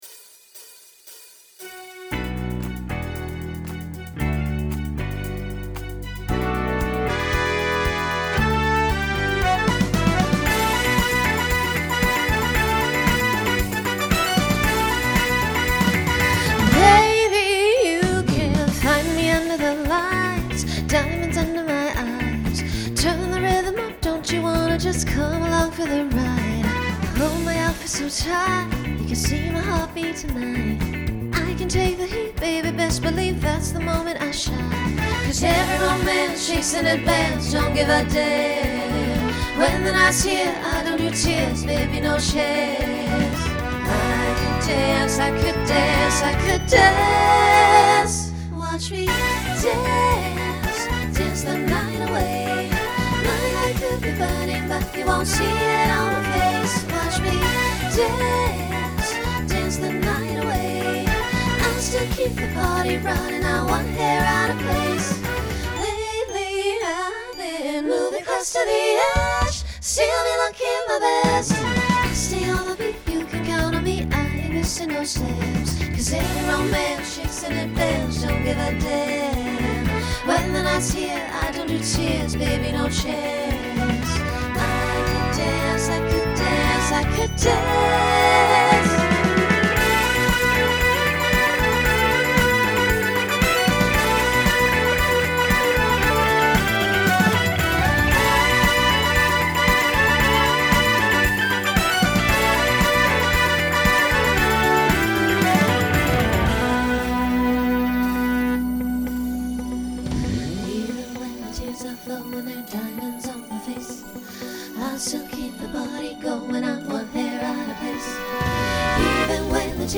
SATB SSA
Broadway/Film , Pop/Dance